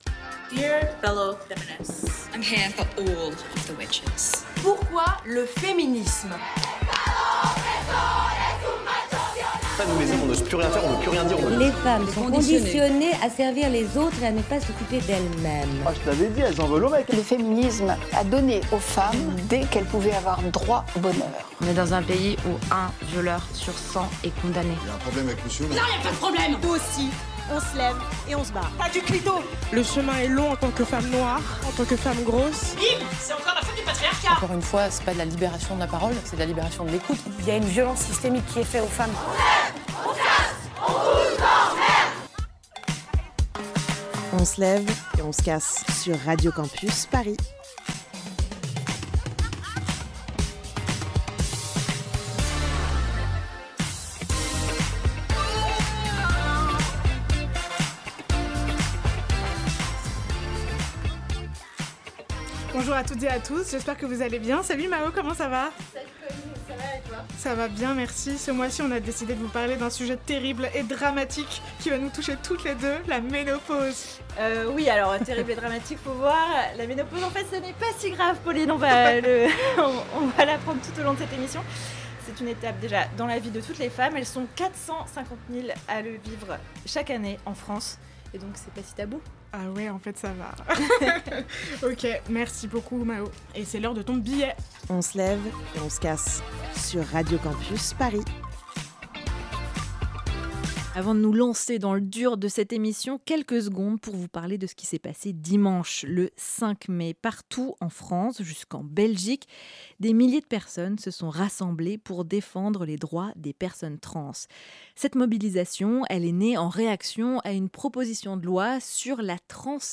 Type Magazine Société